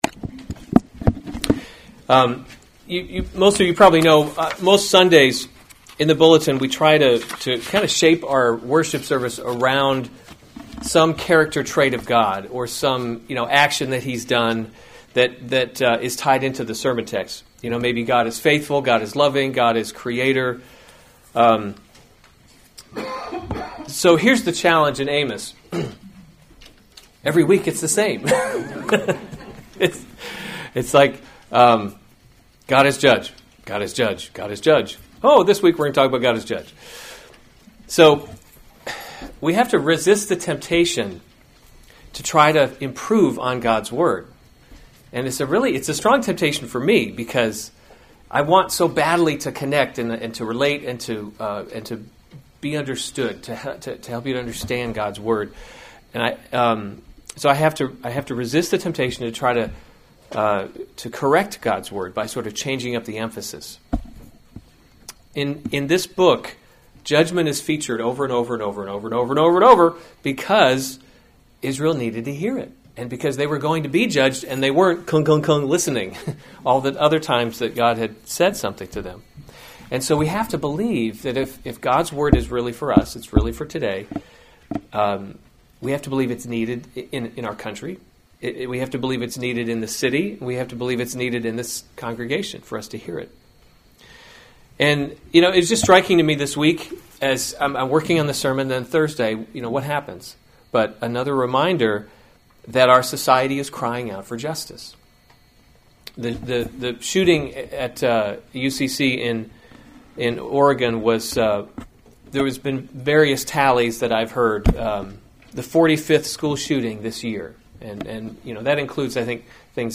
October 3, 2015 Amos: He’s Not a Tame Lion series Weekly Sunday Service Save/Download this sermon Amos 3:1-15 Other sermons from Amos Israel’s Guilt and Punishment 3:1 Hear this word that […]